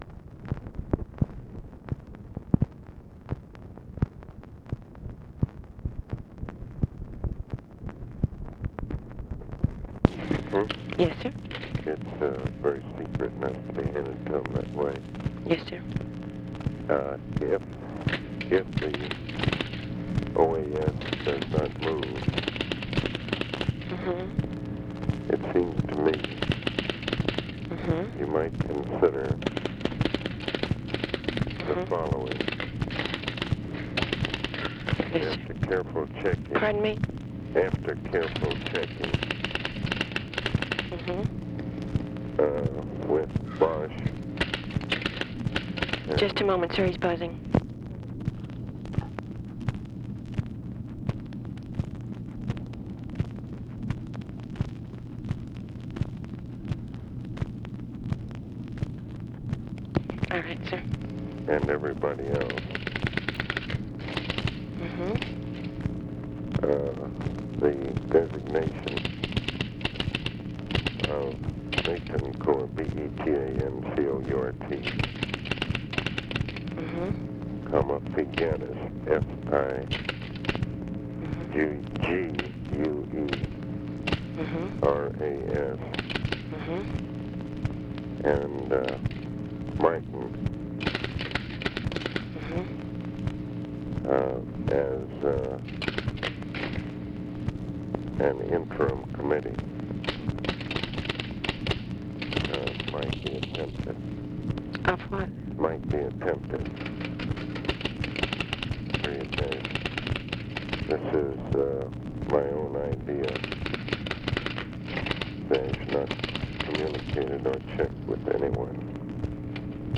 FORTAS DICTATES MESSAGE FOR LBJ ON SITUATION IN DOMINICAN REPUBLIC, SUGGESTING APPOINTMENT OF INTERIM COMMITTEE CONSISTING OF ROMULO BETANCOURT, JOSE FIGUERES FERRER, AND JOHN B. MARTIN IF OAS DOES NOT ACT
Conversation with OFFICE SECRETARY and ABE FORTAS, May 1, 1965
Secret White House Tapes